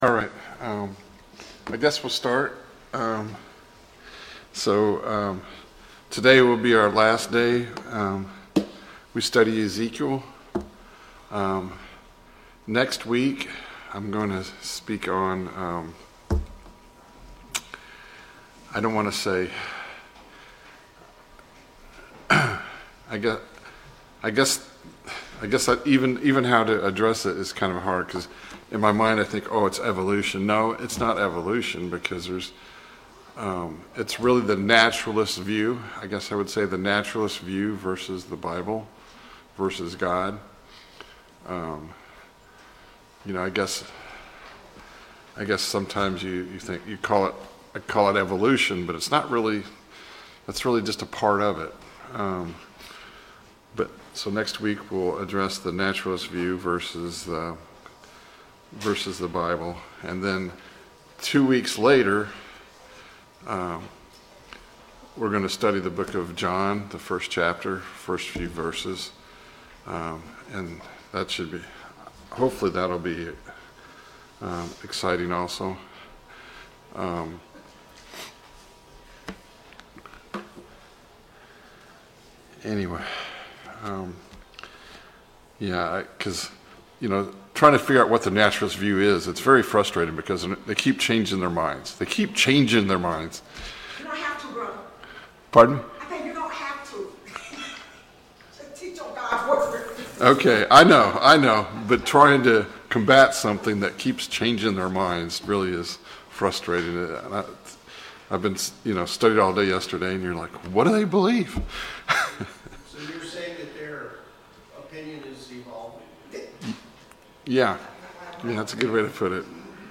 Study of Ezekiel Service Type: Sunday Morning Bible Class « Study of Paul’s Minor Epistles